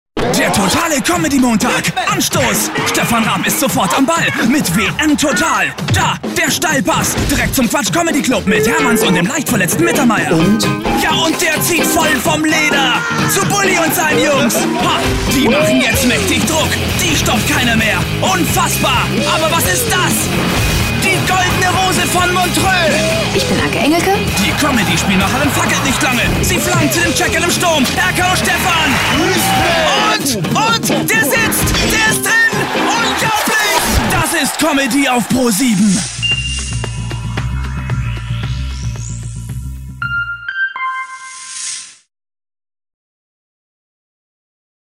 deutscher Sprecher
Kein Dialekt
Sprechprobe: Sonstiges (Muttersprache):
voice over artist german